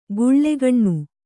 ♪ guḷḷegaṇṇu